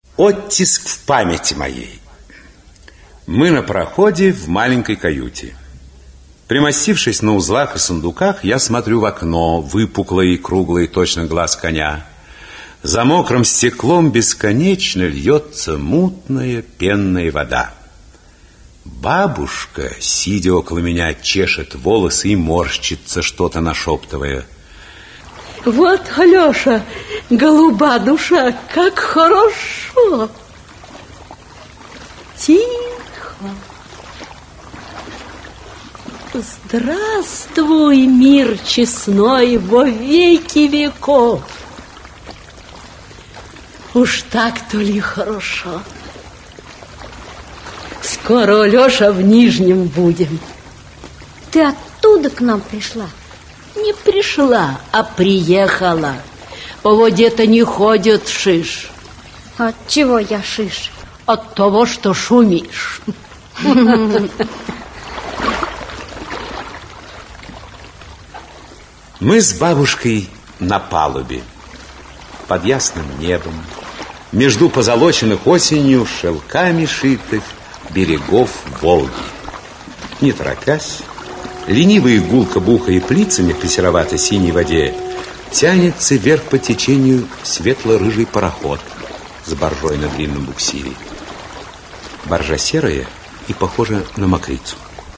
Аудиокнига Алеша Пешков (спектакль) | Библиотека аудиокниг
Aудиокнига Алеша Пешков (спектакль) Автор Максим Горький Читает аудиокнигу Актерский коллектив.